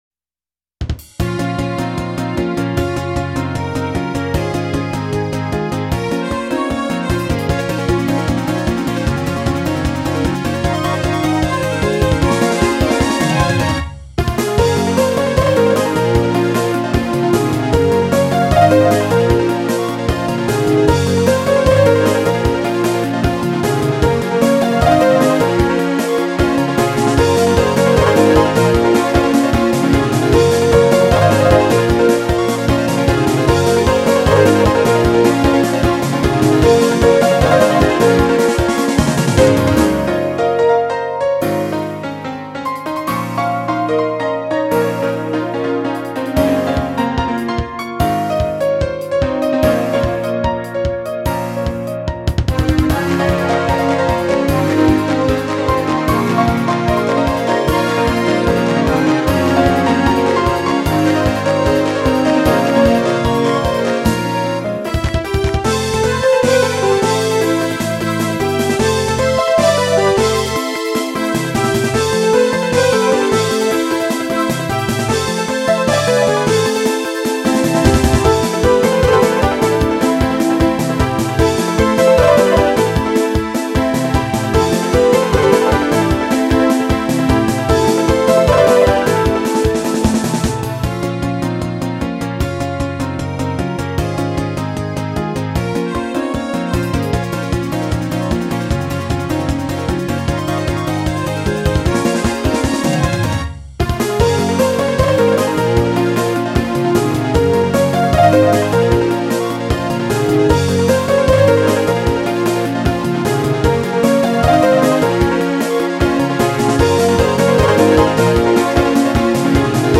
由Roland Sound Canvas 88Pro实机录制